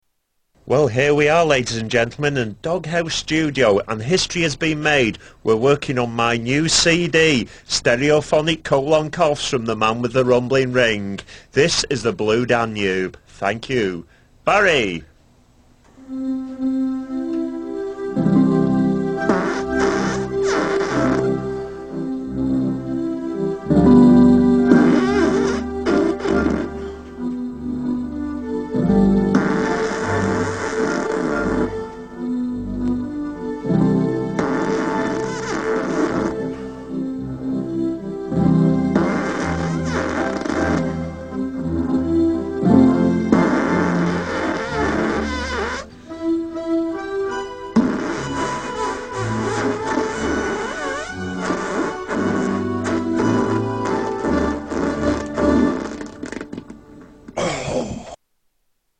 Tags: Comedians Mr Methane Fart Fart Music Paul Oldfield